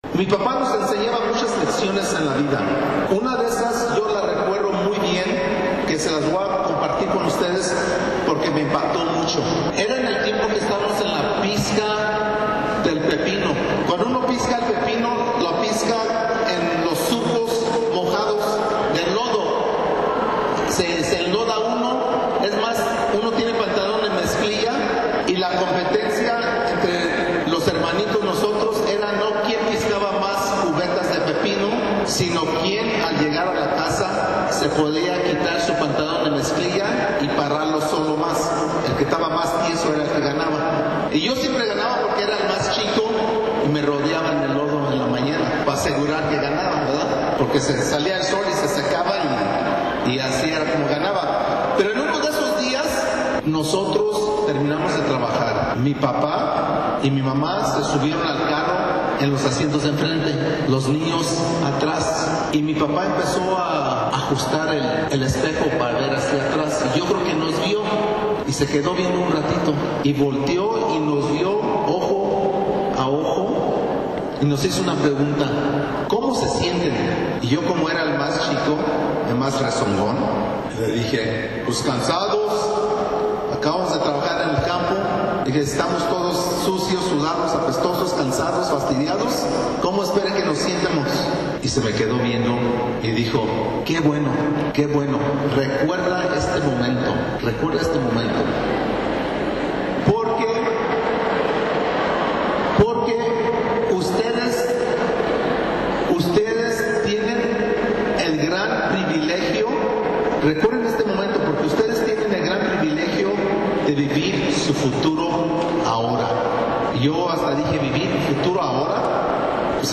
JOSE HERNANDEZ PIEDADENSE DESTACADO HABLO ANTE CIENTOS DE JOVENES SOBRE SU LOGRO DE SER ASTRONAUTA PARA MOTIVARLOS.